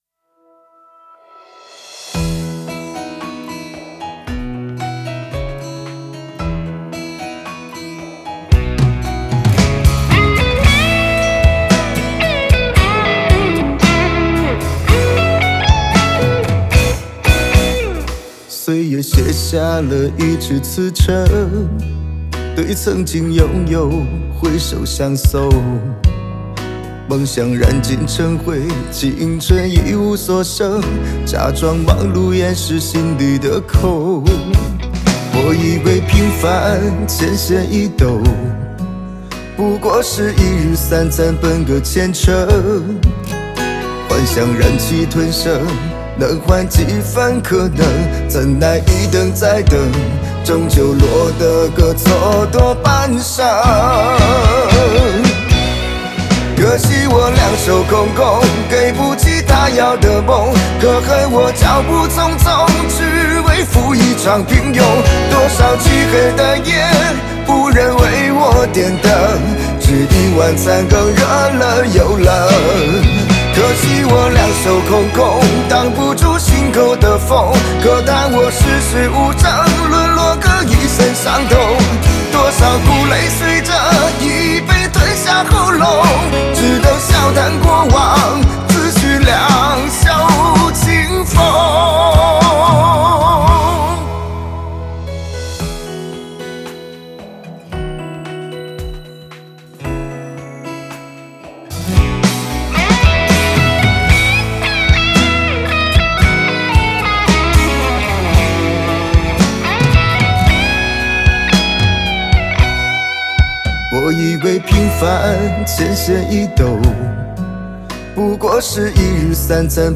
Ps：在线试听为压缩音质节选，体验无损音质请下载完整版
和声Backing Vocal